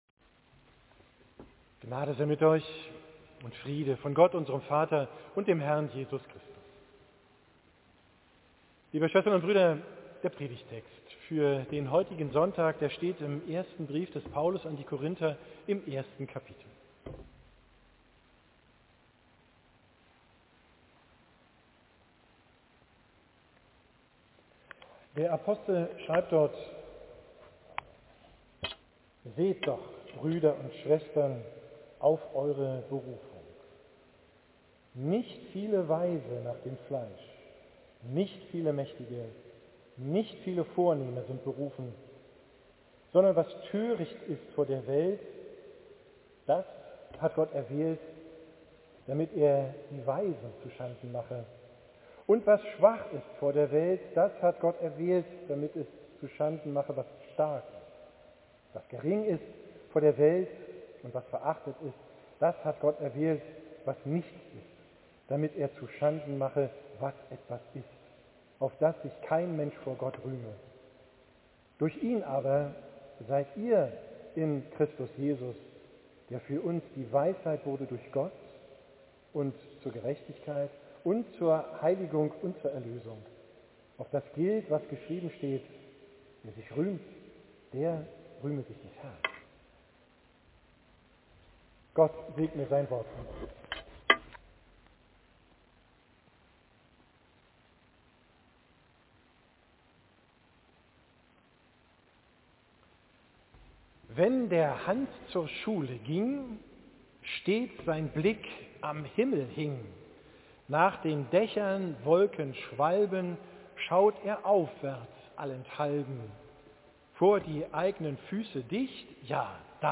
Predigt vom 1. Sonntag nach Epiphanias, 7.